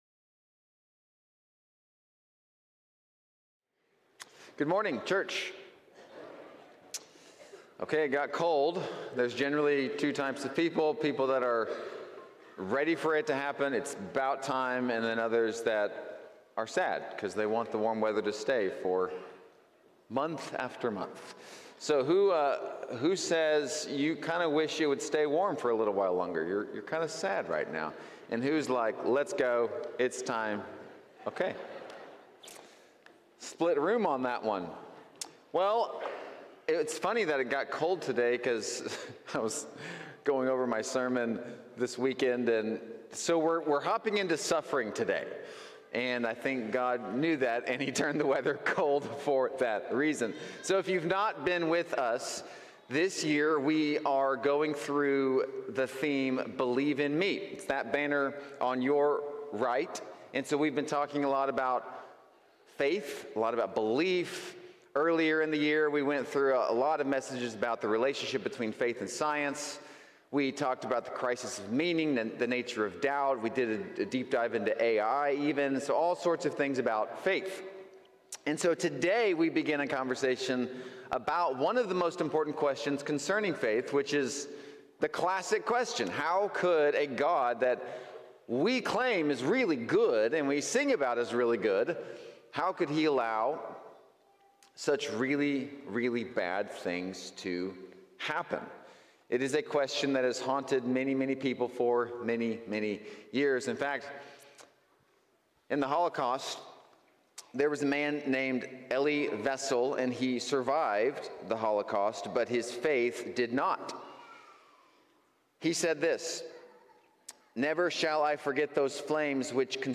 Series: Believe in Me, Sunday Morning